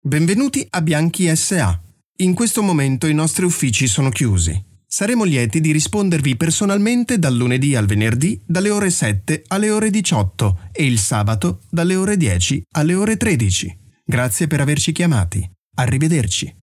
Telefonansage Italienisch (CH)